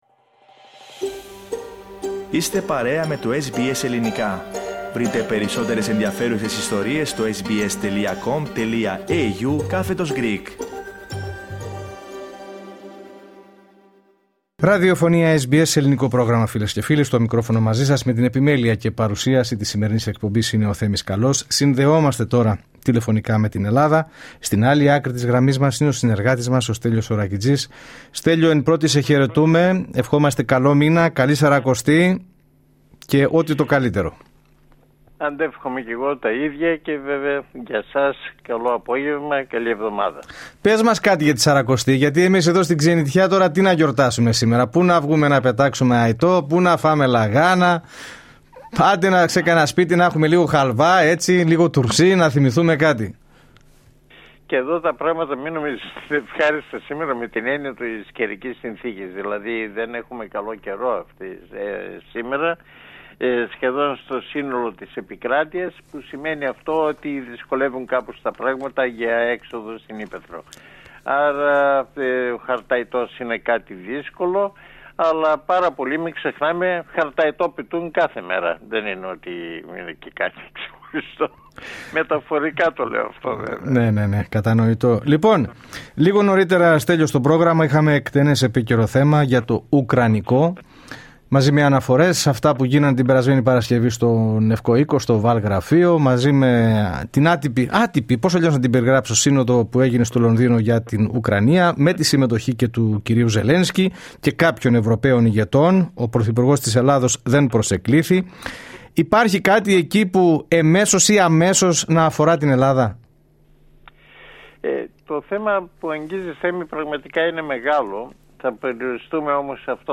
Ακούστε την εβδομαδιαία ανταπόκριση από την Ελλάδα